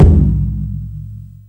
44_07_tom.wav